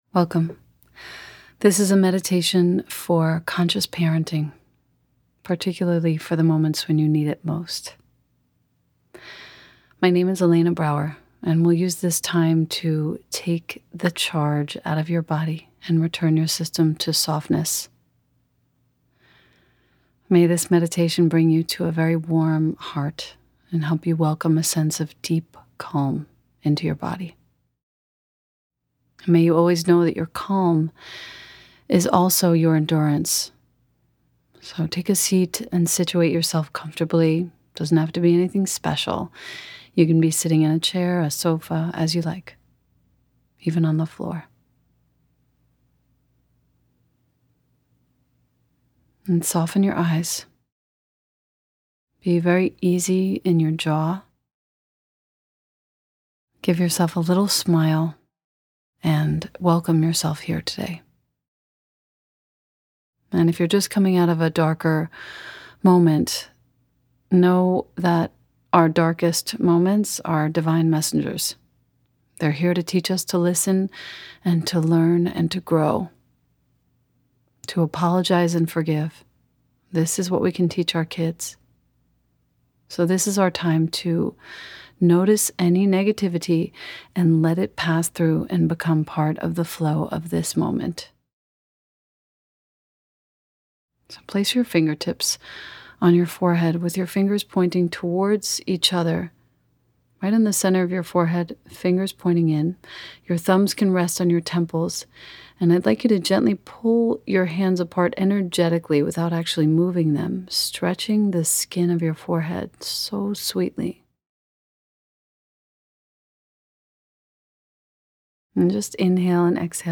Free Gift: Meditation for Conscious Parenting FREE GIFT